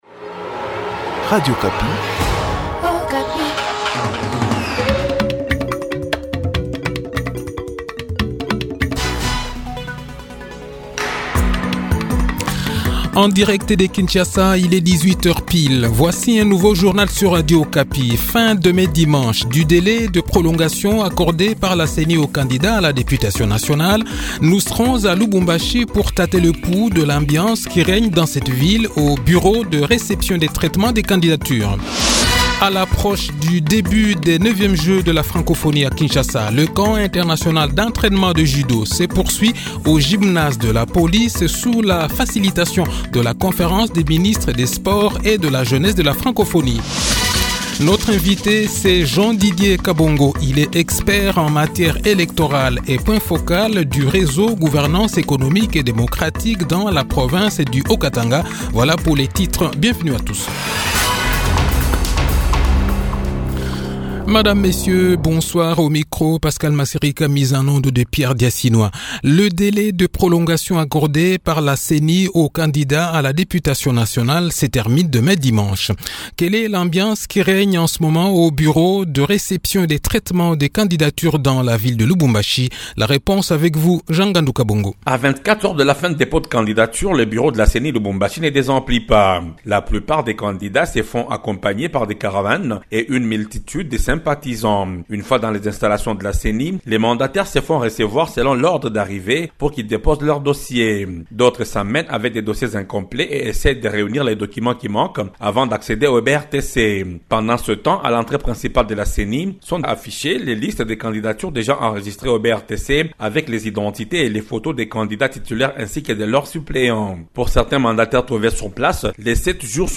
Le journal de 18 h, 22 Juillet 2023